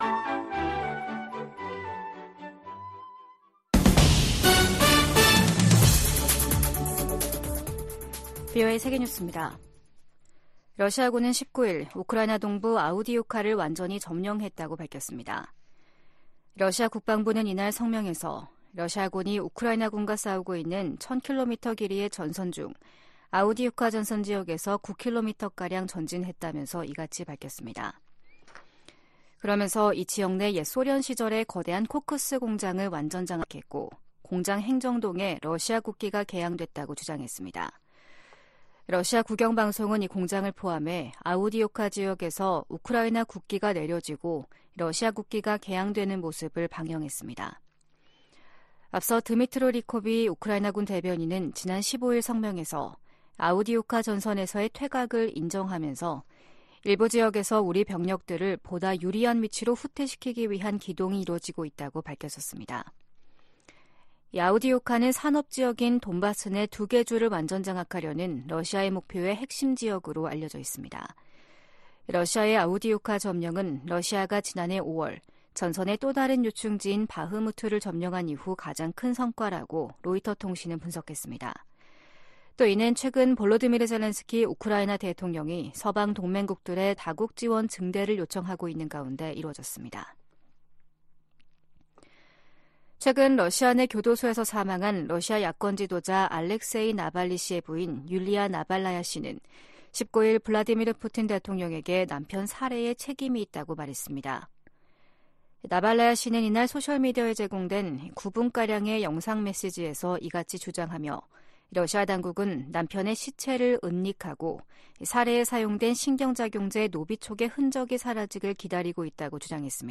VOA 한국어 아침 뉴스 프로그램 '워싱턴 뉴스 광장' 2024년 2월 20일 방송입니다. 미 국무부는 러시아의 북한산 탄도미사일 사용 사실을 확인하고 미국은 모든 수단을 동원해 북러 간 무기 거래를 막을 것이라고 강조했습니다. 북한이 핵과 미사일 역량을 키우면서 미국과 동맹에 대한 위협 수준이 점증하고 있다고 미국 전략사령관이 지적했습니다. 백악관은 북한과 일본 간 정상회담 추진 가능성에 대해 지지 입장을 밝혔습니다.